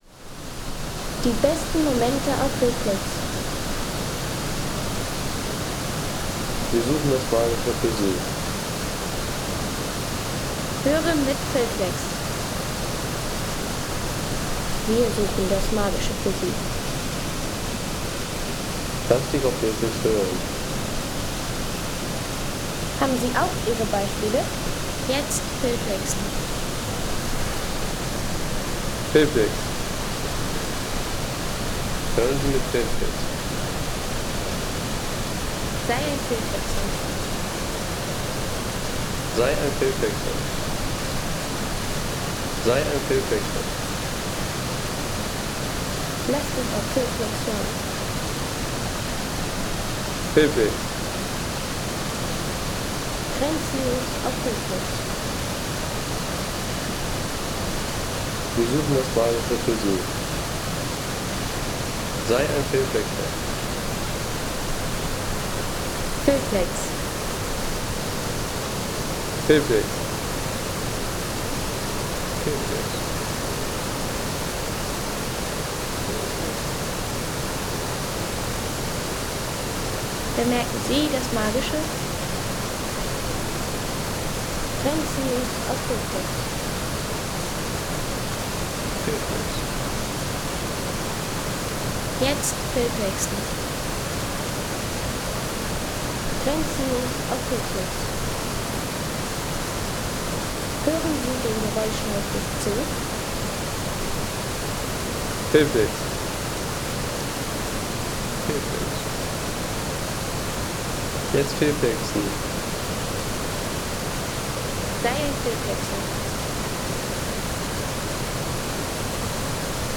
Landschaft - Wasserfälle
Eine beeindruckende Wanderung entlang der sprudelnden Gletscherbäche ... 3,50 € Inkl. 19% MwSt.